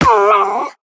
Minecraft Version Minecraft Version latest Latest Release | Latest Snapshot latest / assets / minecraft / sounds / mob / wolf / cute / hurt3.ogg Compare With Compare With Latest Release | Latest Snapshot
hurt3.ogg